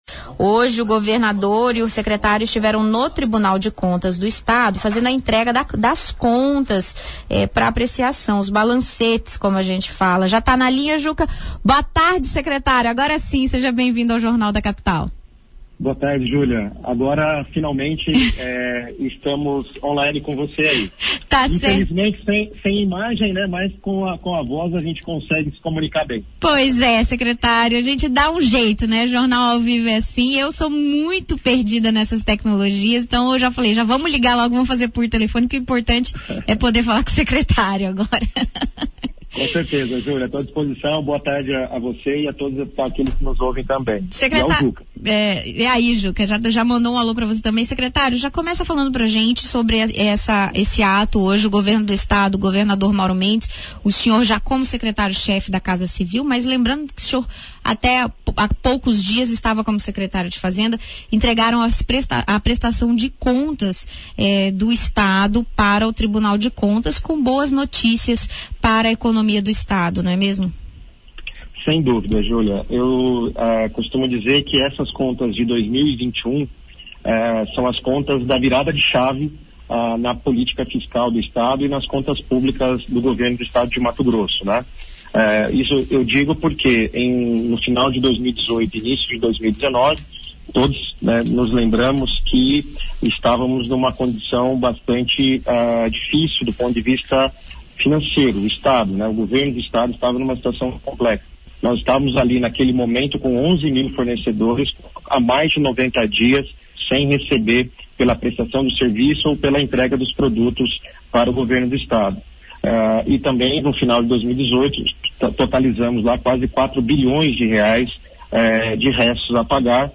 Em entrevista à Rádio Capital de Cuiabá nesta segunda-feira (11/4) à tarde, Gallo veio para a briga na defesa da gestão do governo.